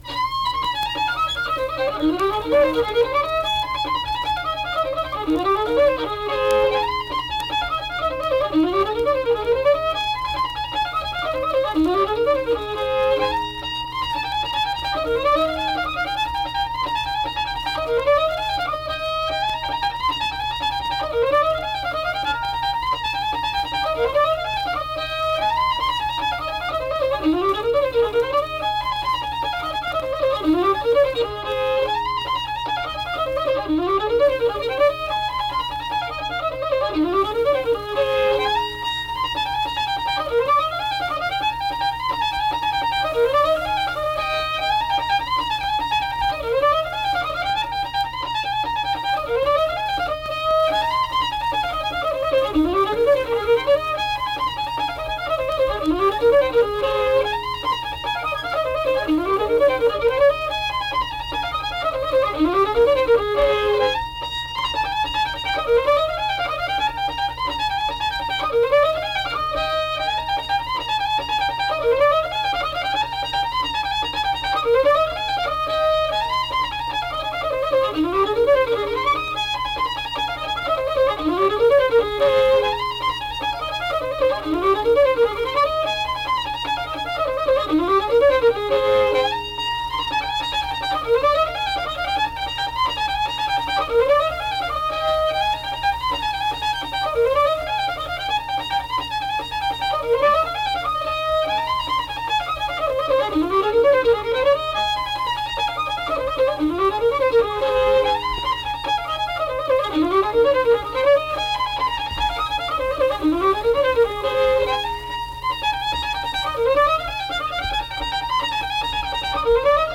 Unaccompanied fiddle music and accompanied (guitar) vocal music
Instrumental Music
Fiddle
Braxton County (W. Va.)